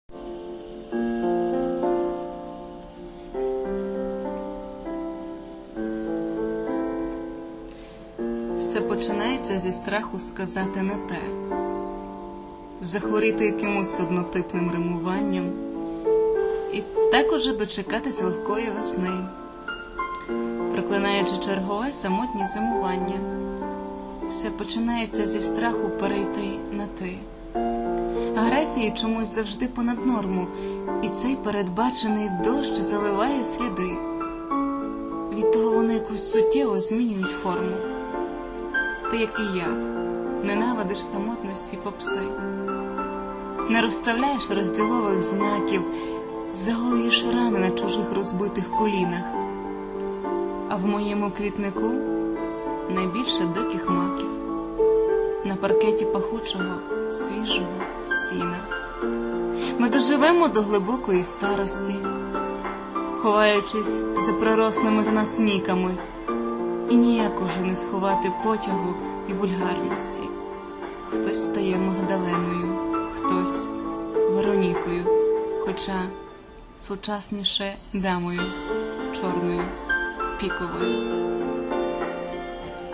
Рубрика: Поезія, Лірика
Дуже приємний, ніжний голос... зачаровує.